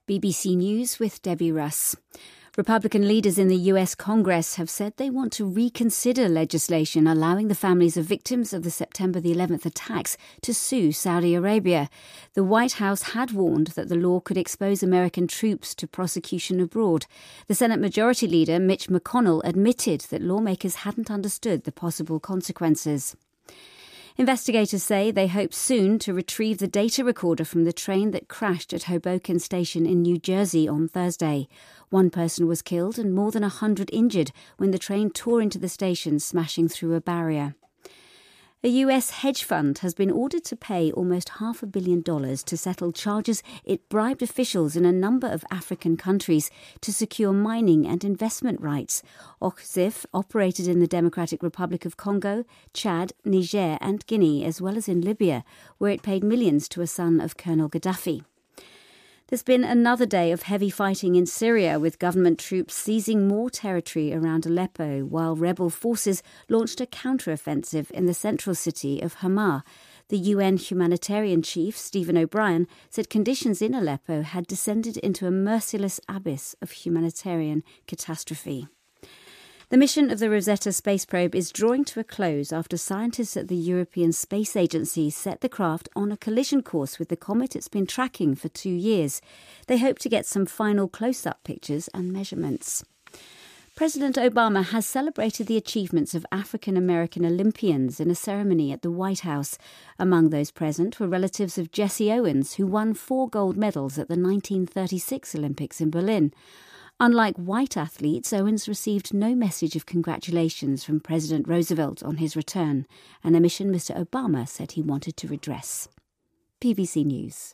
BBC news,美国新泽西火车撞进了车站